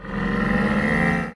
wwildlife_camel.ogg